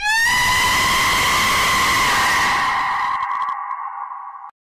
scream rooster gato gritando Meme Sound Effect
scream rooster gato gritando.mp3